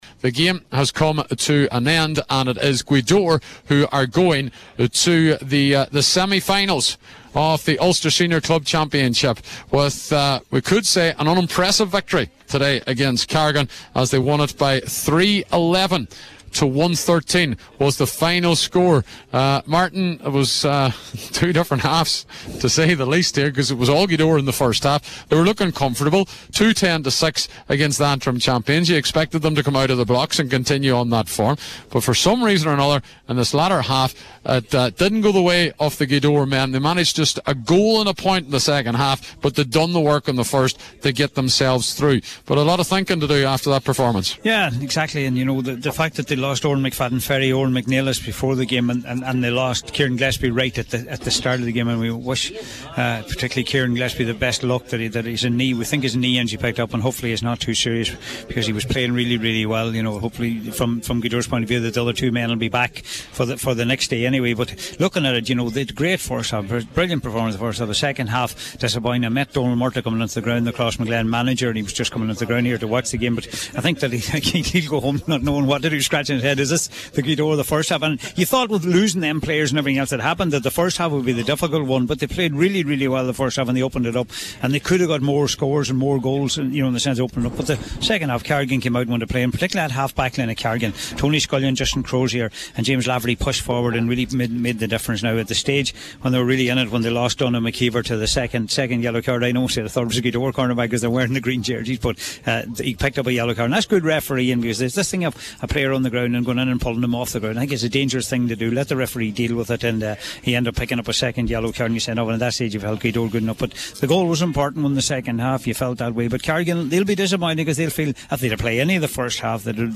spoke at the final whistle…